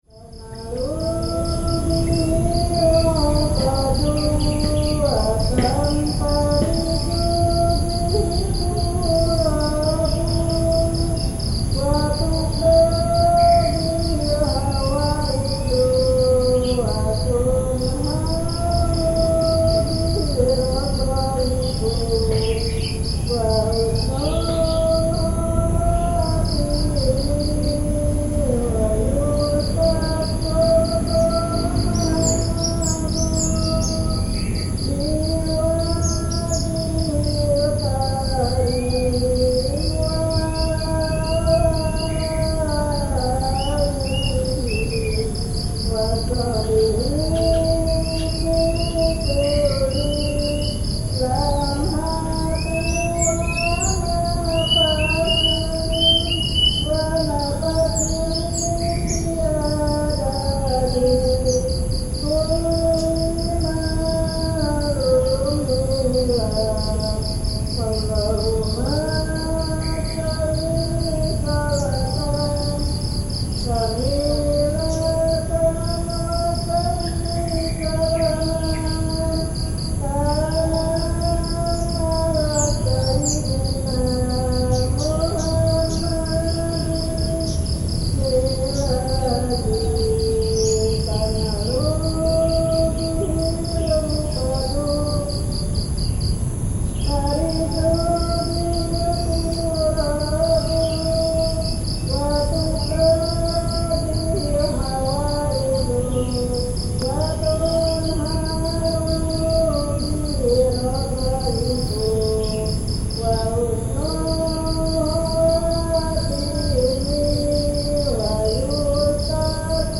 There are various insects and birds in Rumah Tangga while the space is filled with gardens. During the morning time, there used to be a 'sholawat' (a praise to God from a nearby mosque) until noon.
Because of the vast lands, the megaphone sound from the mosque sprawls nicely throughout the area. The sound of the inhabitants gives a calm and soothing feeling.
I was sitting on my bed, behind my recorder, and enjoying the moment.